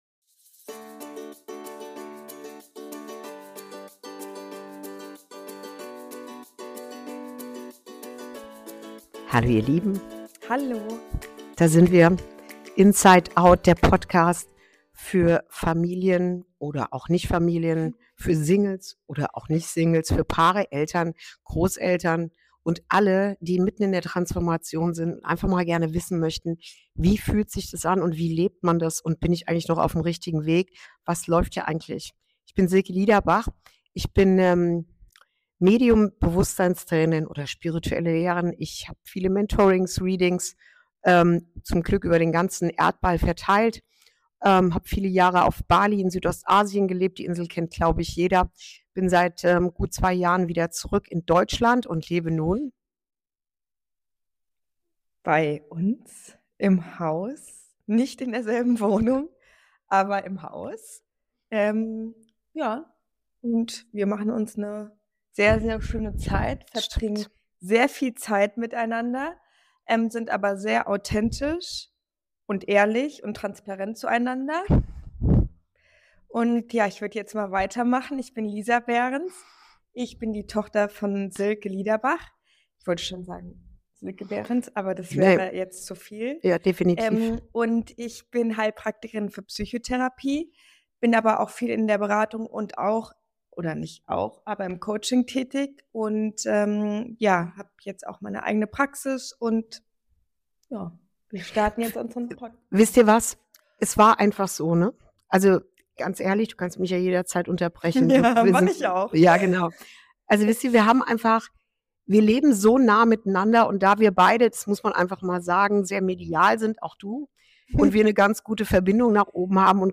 Folge 1: Was bedeutet Transformation wirklich? Eine Reise durch Schmerz, Heilung und Neuanfang ~ Inside Out - Ein Gespräch zwischen Mutter und Tochter Podcast